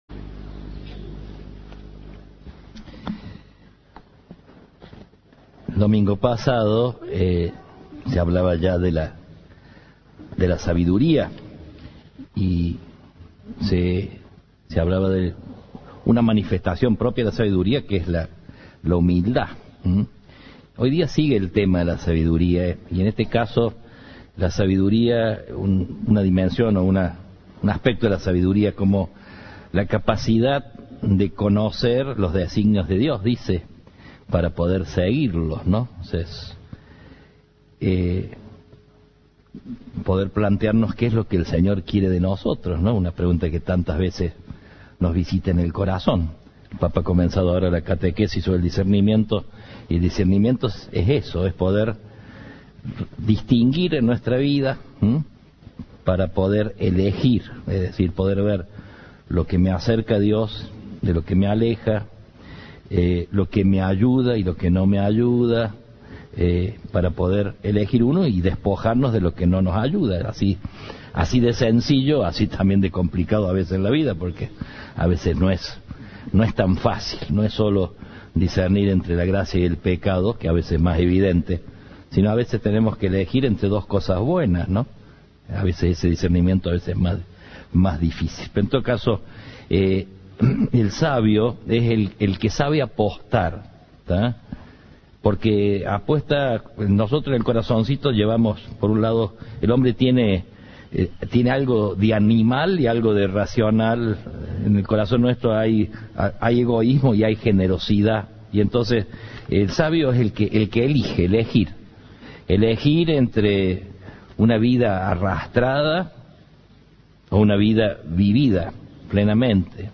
La Iglesia instó a no consentir el desencanto - Santa Misa - Cadena 3 Argentina
Así lo señaló en su homilía el arzobispo Ángel Rossi. Cada uno de nosotros tiene que hacer su propia opción: el desafío de vivir es apostar y mantener apuesta, expresó.